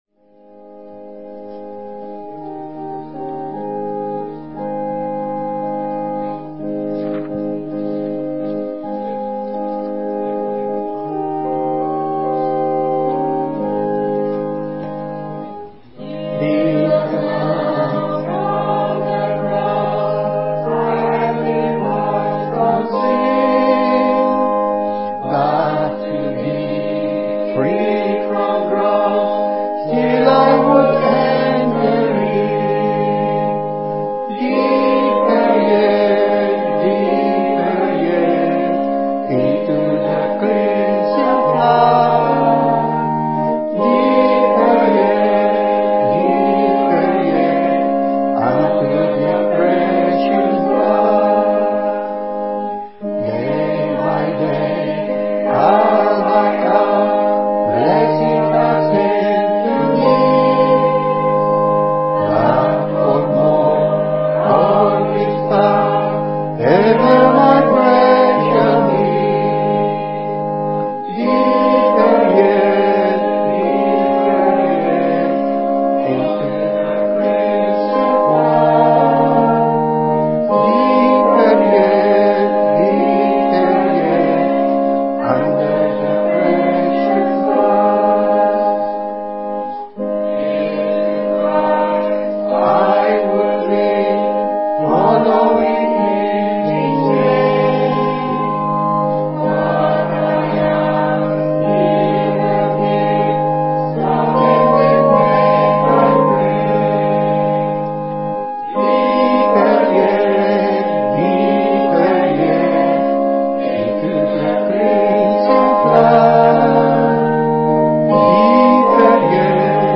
Special performances
btn5 SP-1102-2 Deeper Yet Hymn 302 - Hamilton Congregation